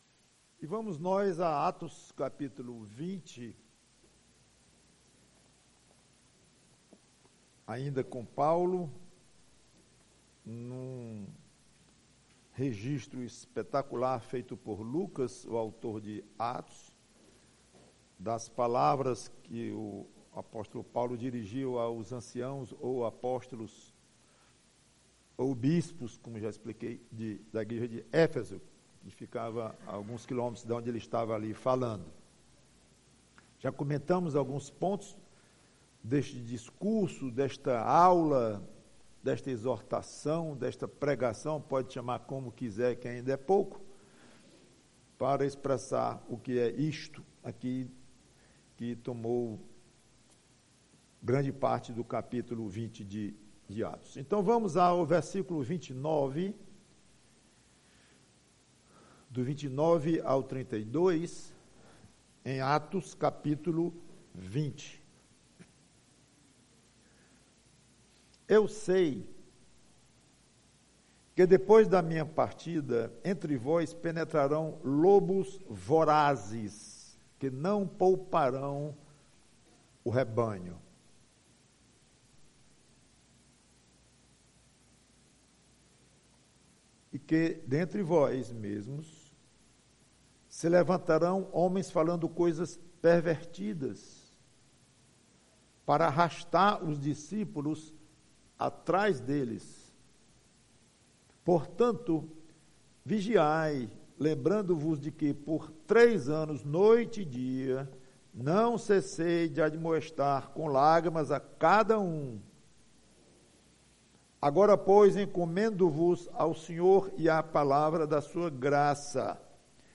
PREGAÇÃO Vigiar e vigiar!